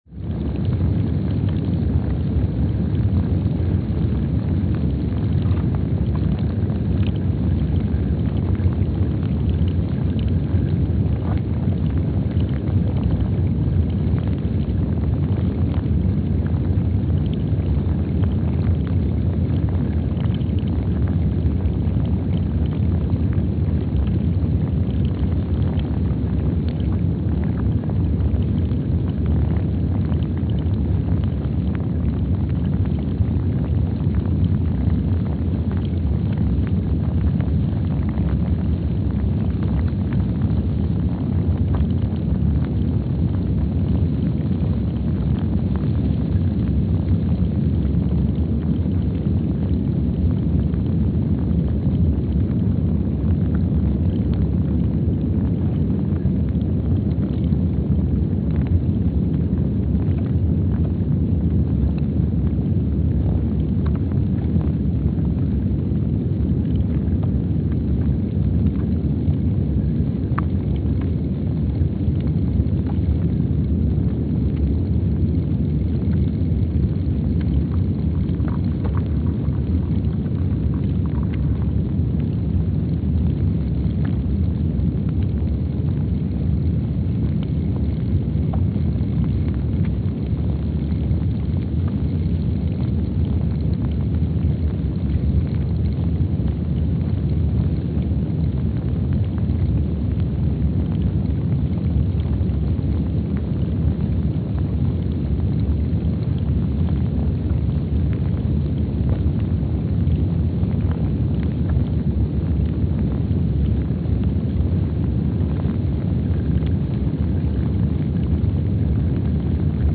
Palmer Station, Antarctica (seismic) archived on August 11, 2025
Station : PMSA (network: IRIS/USGS) at Palmer Station, Antarctica
Sensor : STS-1VBB_w/E300
Speedup : ×500 (transposed up about 9 octaves)
Loop duration (audio) : 05:45 (stereo)
SoX post-processing : highpass -2 90 highpass -2 90